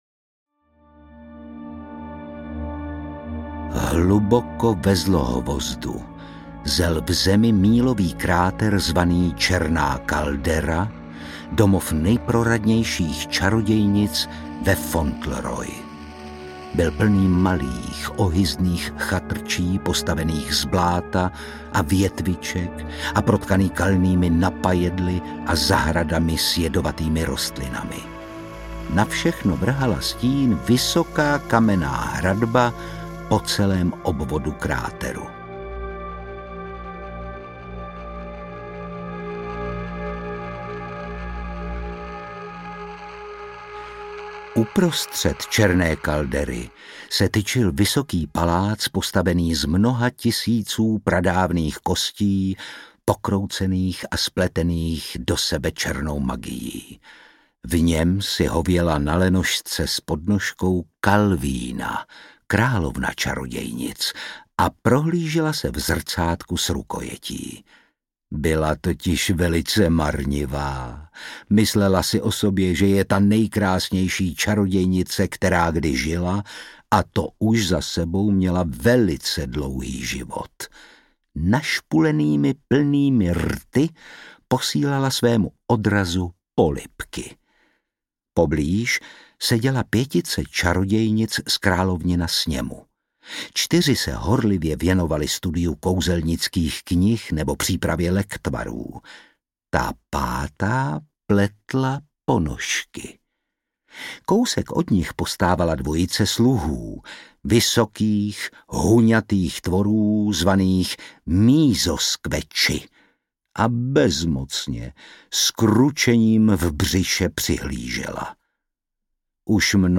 Warren XIII. a šeptající les audiokniha
Ukázka z knihy
Čtou Ondřej Brousek, Otakar Brousek.
Hudba Ondřej Brousek. Vyrobilo studio Soundguru.